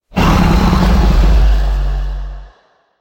growl2.mp3